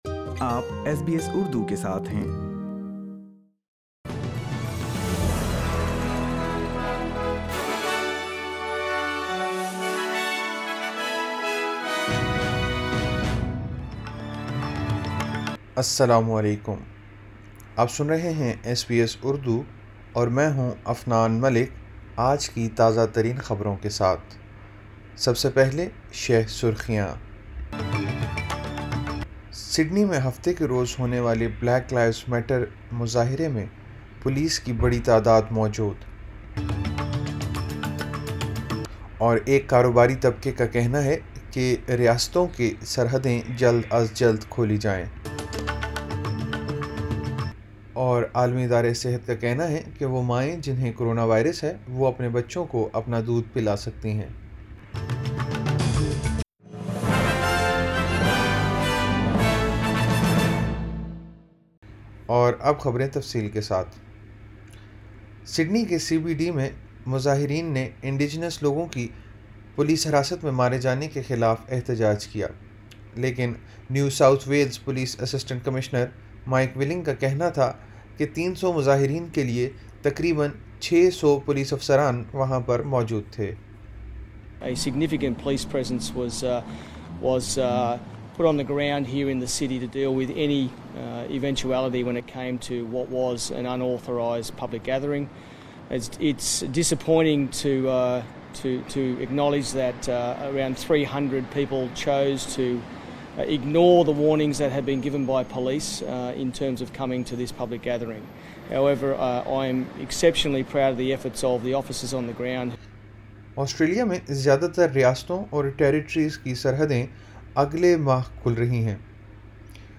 ایس بی ایس اردو خبریں 13 جون 2020